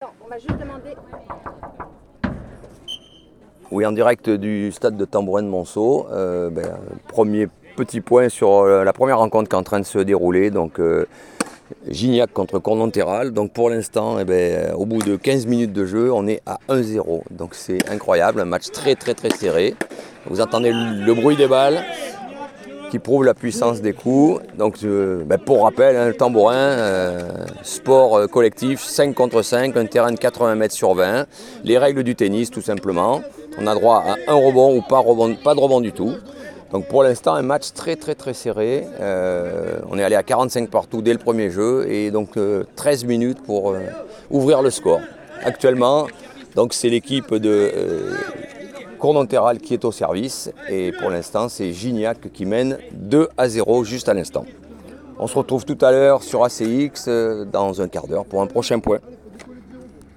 tambourins3.mp3